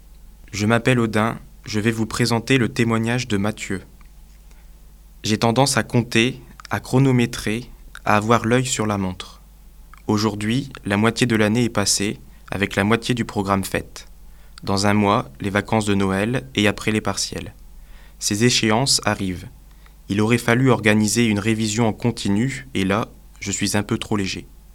étudiants français et chinois de l'Université Lille 1 qui ont prêté leur voix.